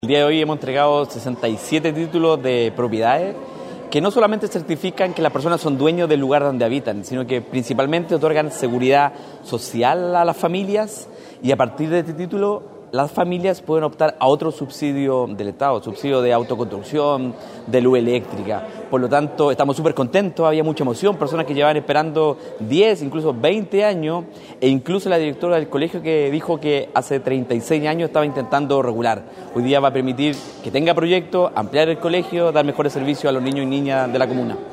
En el salón principal de la casa de la cultura de la comuna de Illapel se llevó a cabo la entrega de 67 títulos de domino a beneficiarios y beneficiarias de la capital provincial, instancia que fue encabezada por el Subsecretario de Bienes Nacionales Sebastián Vergara, quien junto a la Delegada Presidencial provincial de Choapa, Nataly Carvajal, el Seremi de Bienes Nacionales, Marcelo Salazar y el edil comunal, Denis Cortes Aguilera, apreciaron como se cristalizaba el sueño de estas familias illapelinas de ser propietarias del inmueble que habitan.
Mientras que el Subsecretario de Bienes Nacionales, Sebastián Vergara expresó que